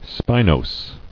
Spi*nos"i*ty , n. The quality or state of being spiny or thorny; spininess.